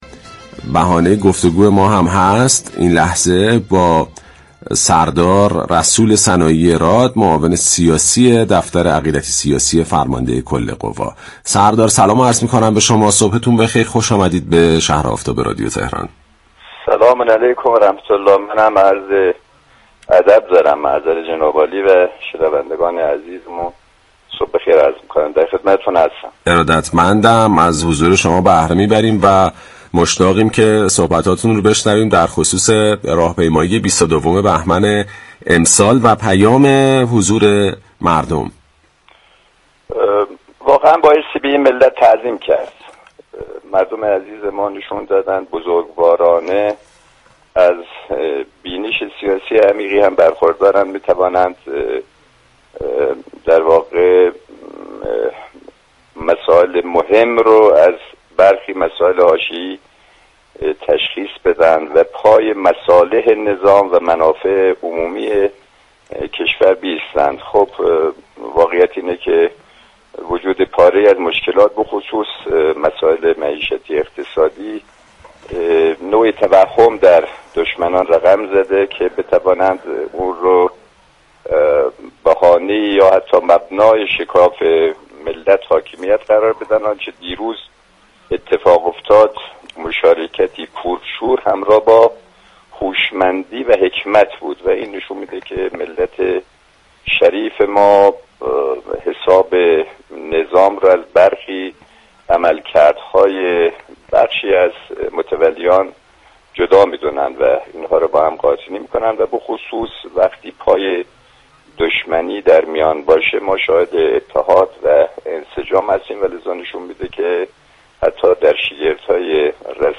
به گزارش پایگاه اطلاع رسانی رادیو تهران، سردار رسول سنایی راد معاون سیاسی دفتر عقیدتی سیاسی فرماندهی معظم كل قوا در گفت و گو با «شهر آفتاب» درخصوص حضور پرشكوه و گسترده مردم در راهپیمایی 22 بهمن اظهار داشت: باید در مقابل ملت ایران تعظیم كرد؛ مردم بار دیگر اثبات كردند از بینش سیاسی عمیقی برخوردار هستند و مسائل مهم را از برخی مسائل حاشیه‌ای تشخیص می‌دهند و پای مصالح نظام و منافع عمومی كشور ایستاده‌اند.